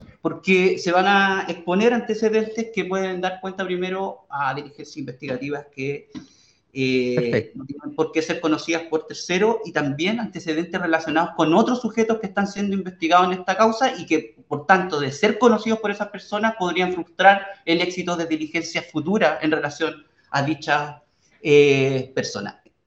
Al comenzar la formalización este jueves, el fiscal, Jaime Calfil, pidió la reserva de los antecedentes que se expondrían y de las diligencias que continúan en curso, solicitando, así, el retiro de los medios de comunicación y el público en general.